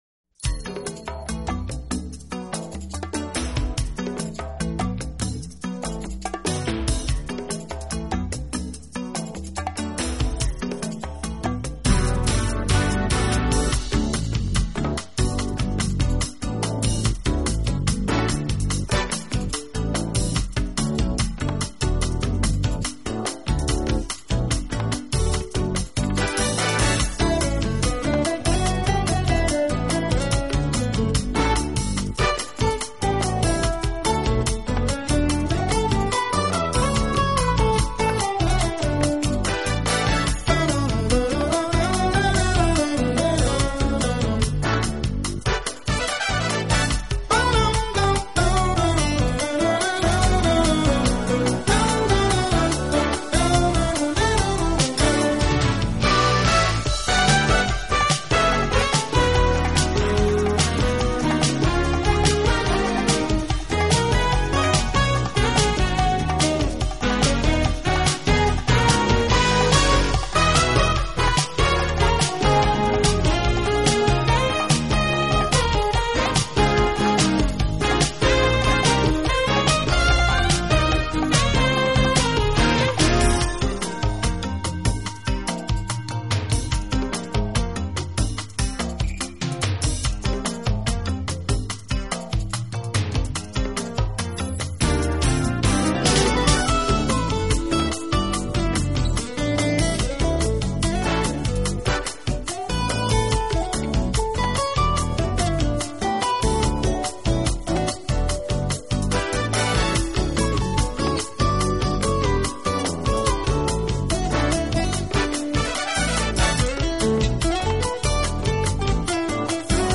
音乐风格：Jazz